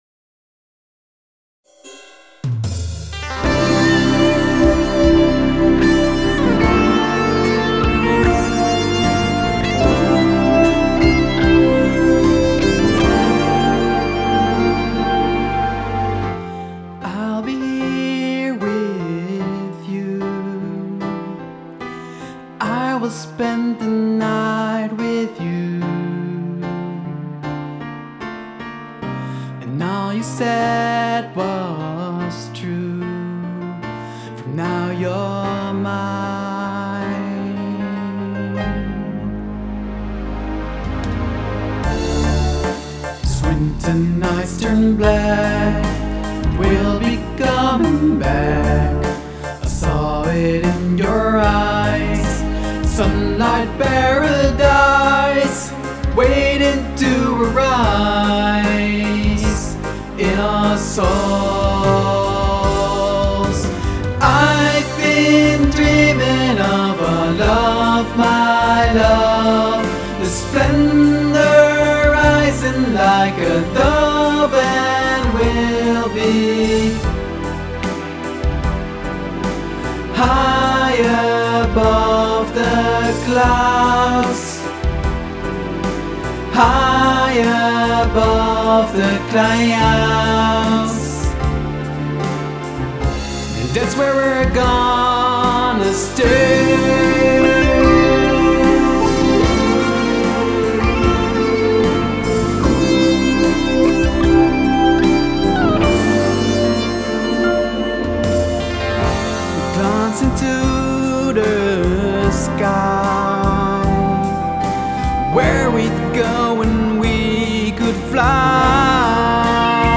toetsen en gitaren
zang en koortjes